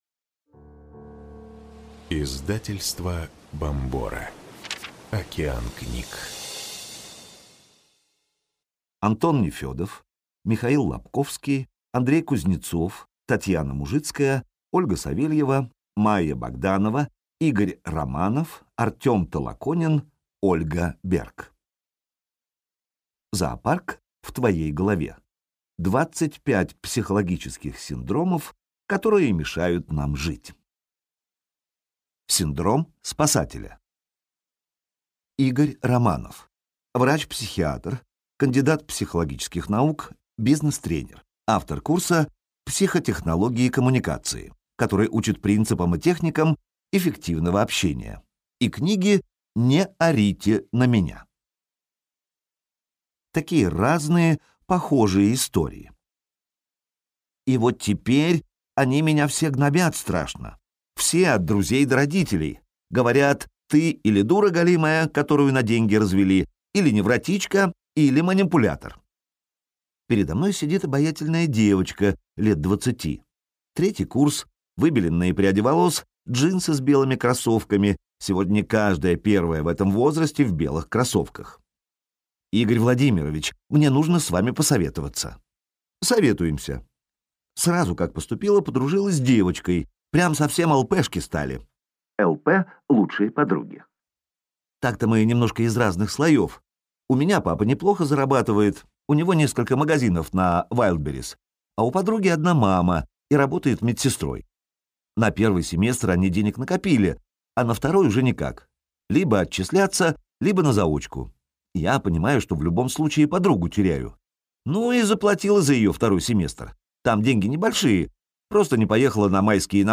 Зоопарк в твоей голове. 25 психологических синдромов, которые мешают нам жить (слушать аудиокнигу бесплатно) - автор Татьяна Мужицкая
Слушать аудиокнигу Зоопарк в твоей голове. 25 психологических синдромов, которые мешают нам жить полностью, читает: Валерий Кухарешин